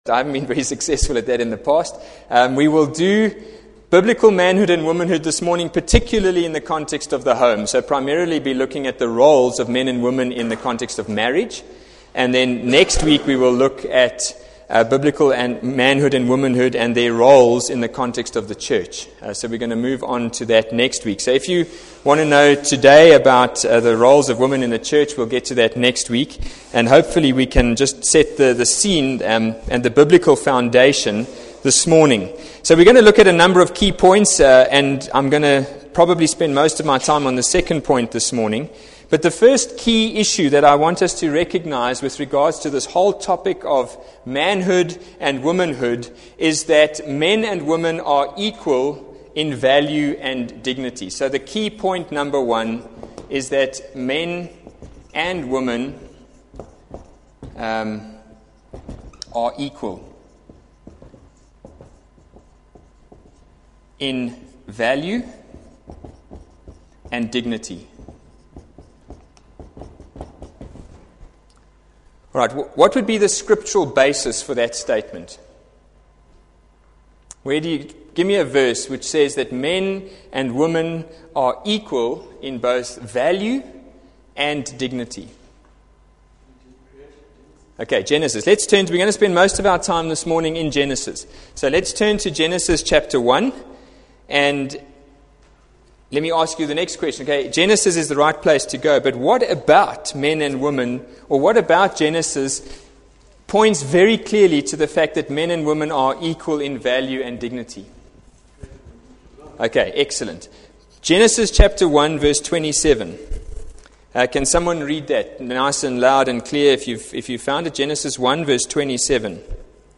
Adult Bible Class - Biblicle Manhood Womanhood - 1.mp3